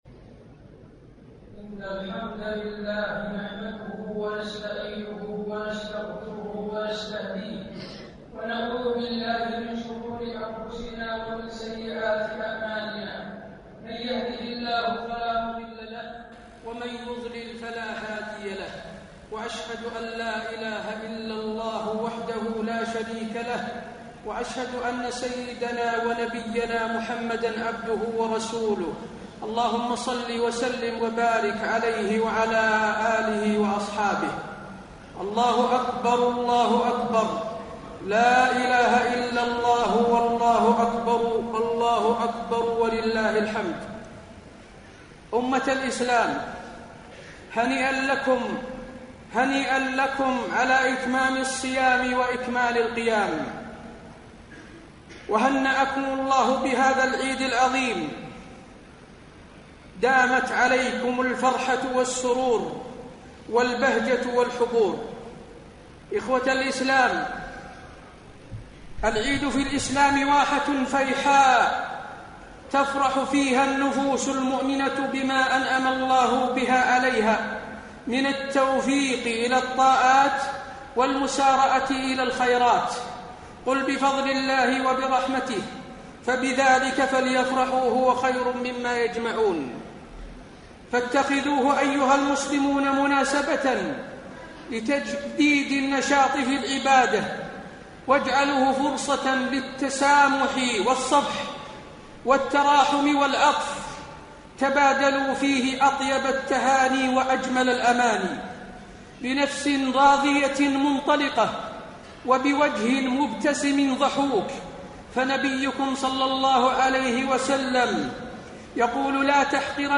خطبة عيد الفطر- المدينة - الشيخ حسين آل الشيخ